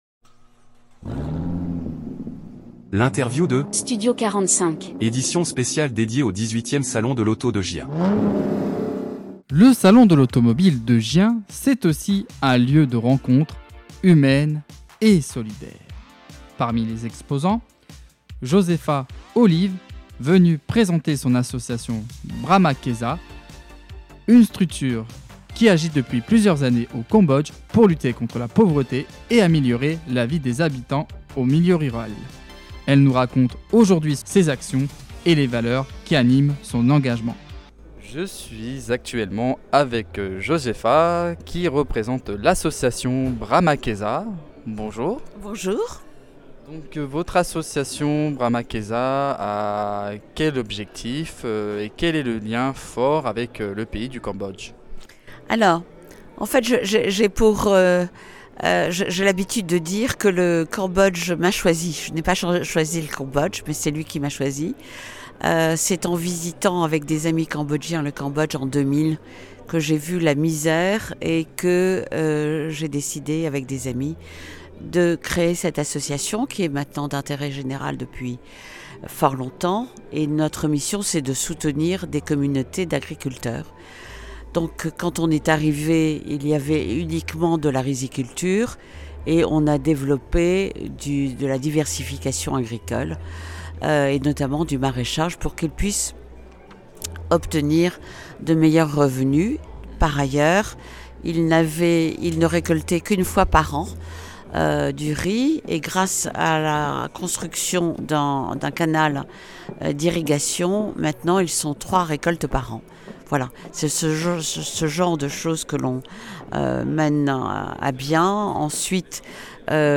Interview Studio 45 - édition spéciale Salon de l'Auto Gien 2025 - Brahma Kesa